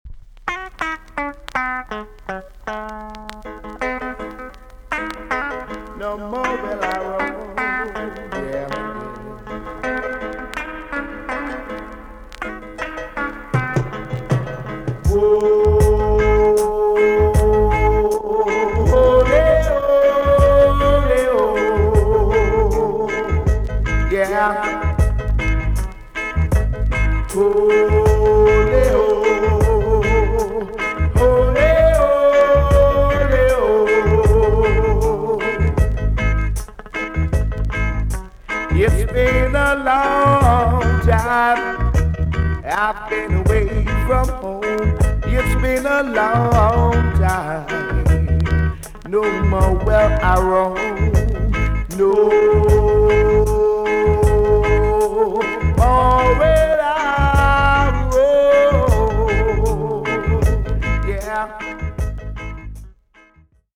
B.SIDE EX- 音はキレイです。